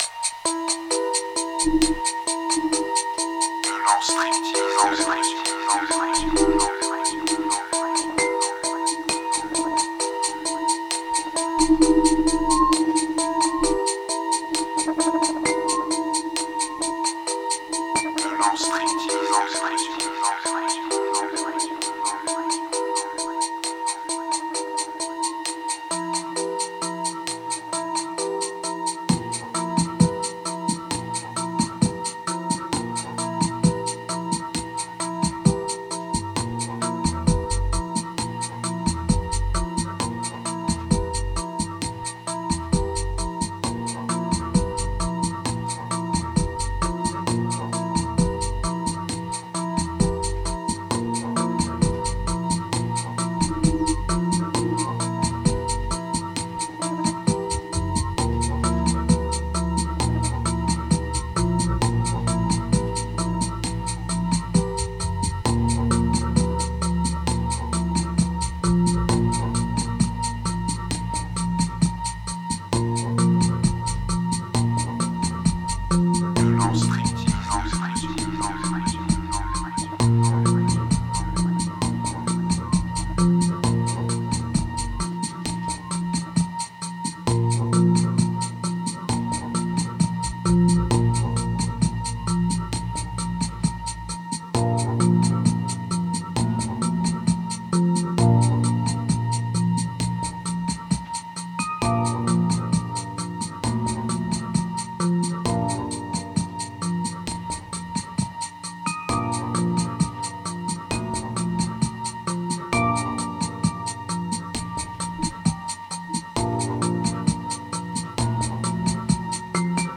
Ambient Midnight Theme Closure Isolation Forbidden Grave